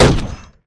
poly_shoot_sniper.wav